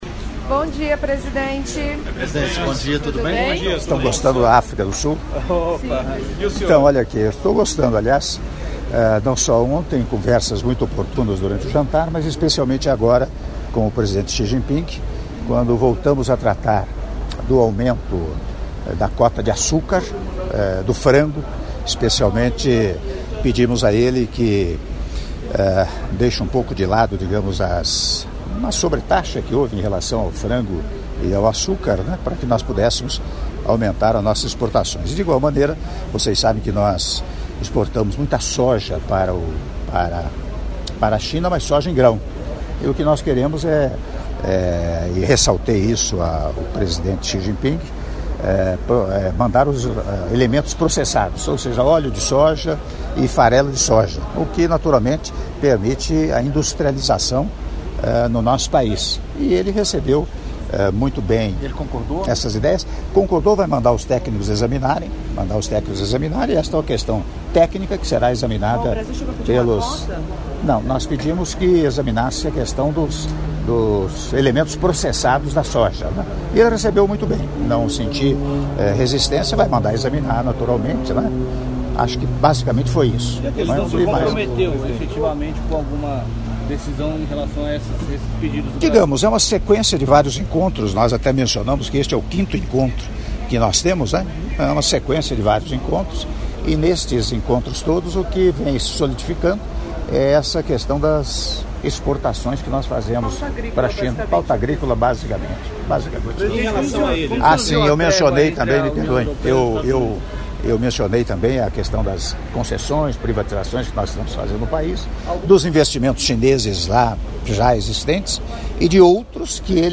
Áudio da Entrevista coletiva concedida pelo Presidente da República, Michel Temer, após Encontro bilateral com o Presidente da República Popular da China, Xi Jinping -Joanesburgo/África do Sul- (02min49s)